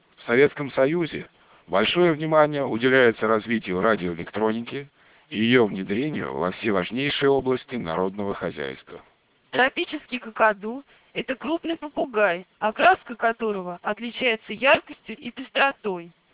Для сравнения ниже приведены данные, полученные в аналогичных условиях для стандартного MELP вокодера федерального стандарта США со скоростью 2400 бит/с:
Речь в канале без ошибок
melp2400.wav